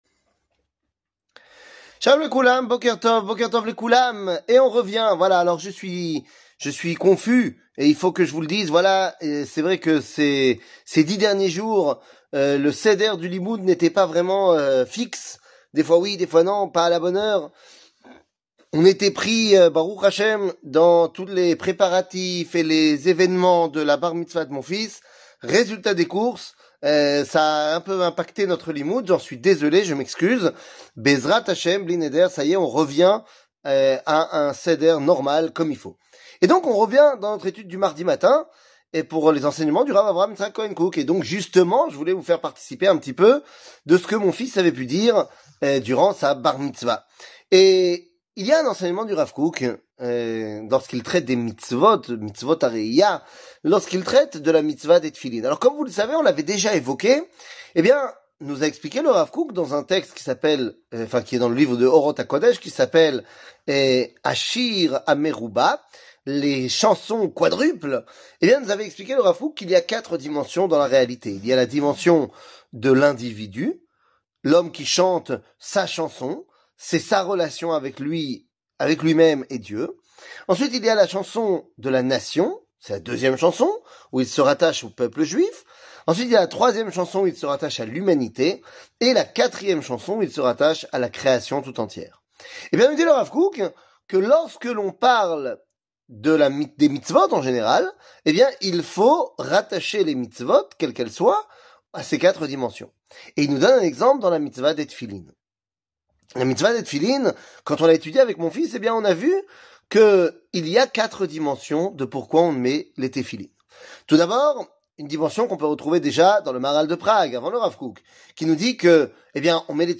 שיעור מ 06 פברואר 2024